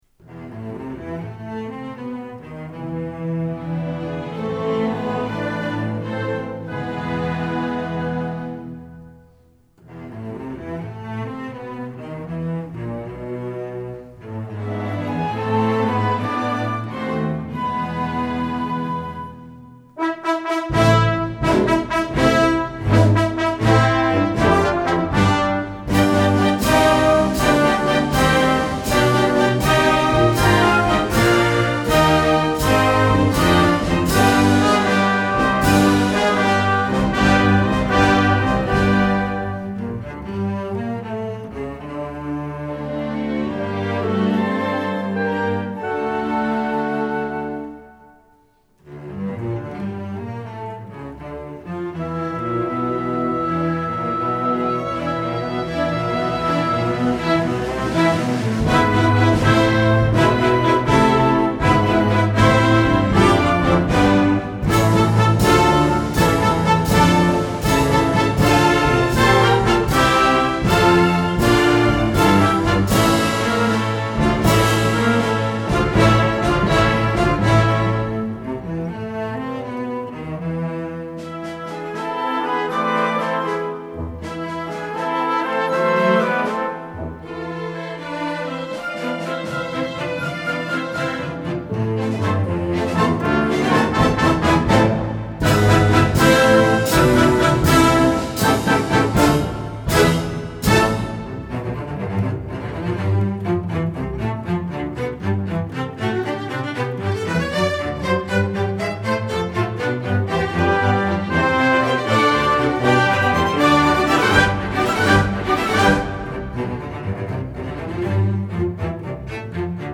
Voicing: Full Orchestra